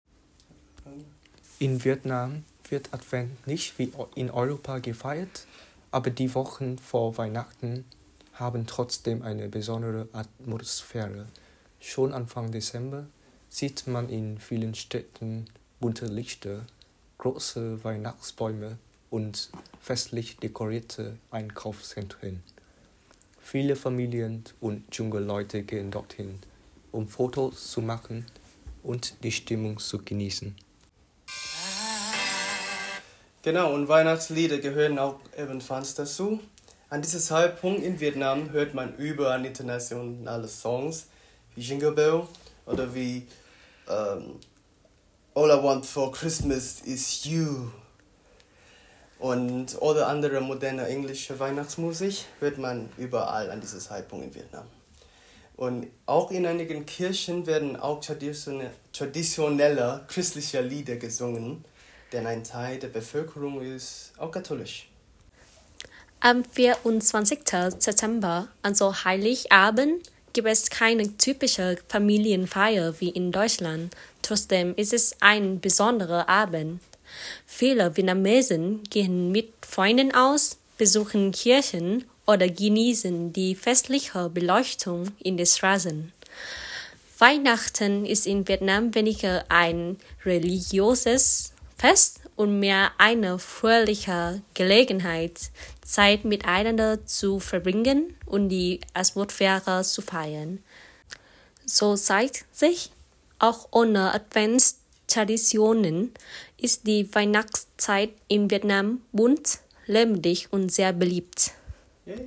In unserem KHG-Adventskalender, der schon am 1. Advent beginnt, öffnen sich zu den Adventssonntagen die Türchen mit Stimmen unserer KHG-Mitglieder aus aller Welt. Sie teilen persönliche Erfahrungen, Zeichen der Hoffnung und kleine Impulse:  Wie sieht die Weihnachtsvorfreude anderswo aus?